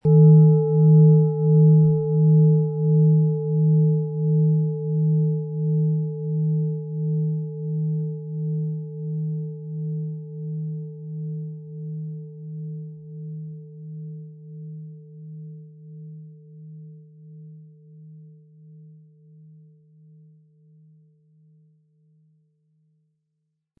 Planetenton 1 Planetenton 2
Ihr Klang ist wärmer, weicher und trägt etwas Zeitloses in sich.
Zusätzlich klingt der Venus-Ton mit - eine liebevolle Schwingung, die zu Harmonie, Schönheit und Beziehungsfähigkeit führt.
Sie möchten den Original-Ton der Schale hören? Klicken Sie bitte auf den Sound-Player - Jetzt reinhören unter dem Artikelbild.